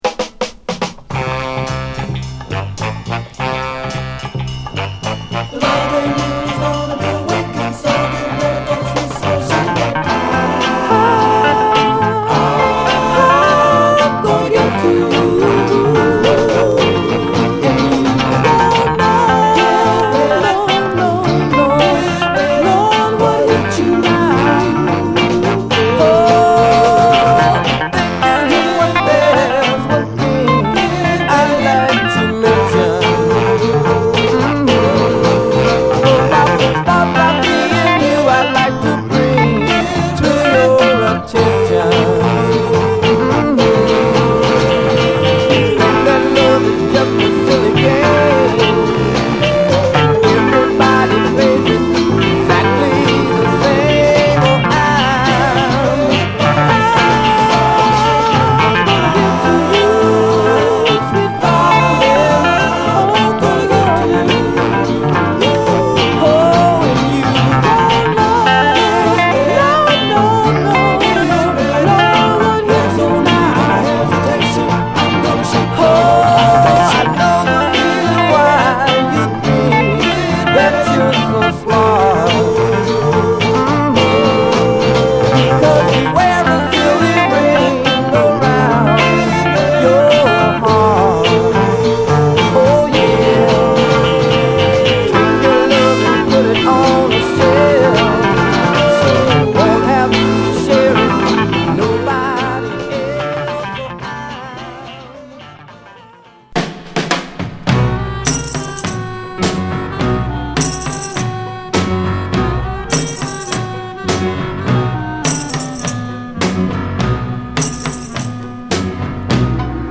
4人組(後に3人)ヴォーカル・グループ
素晴らしいヤング・ソウルを収録！